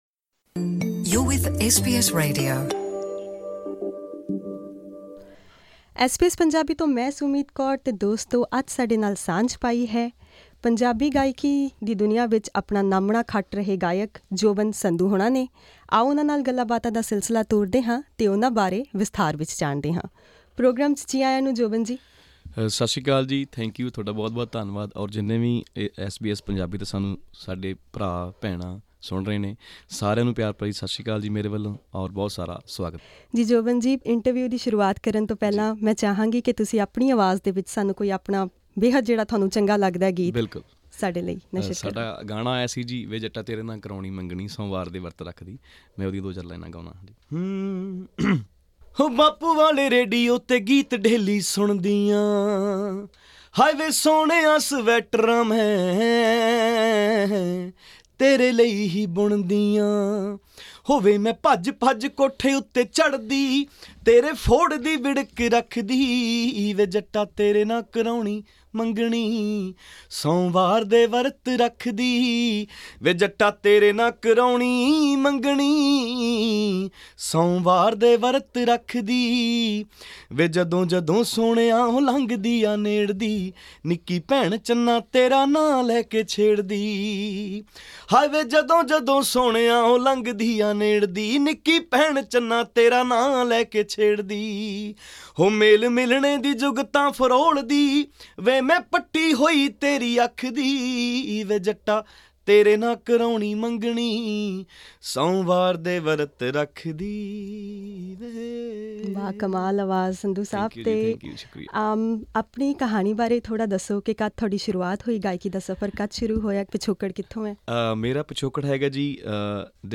Punjabi singer